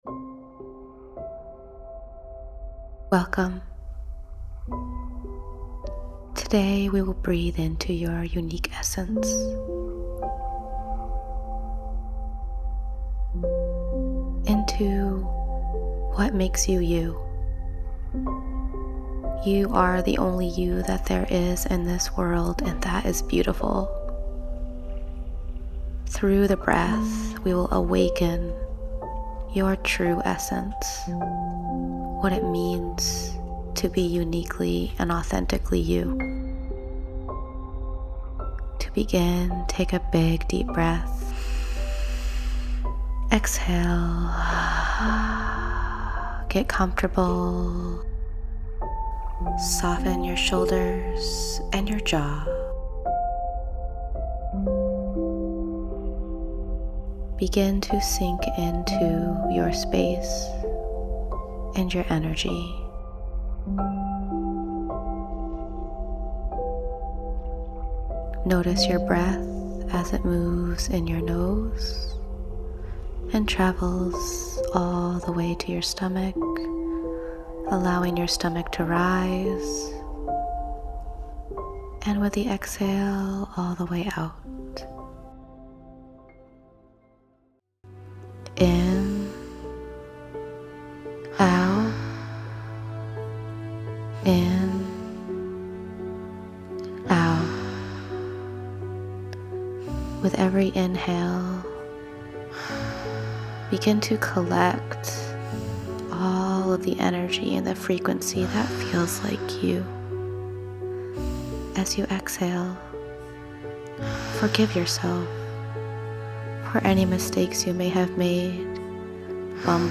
Heart-centered professionally guided Breathwork sessions to calm, clear, and energize.
Easy to follow breaths with intuitively chosen music.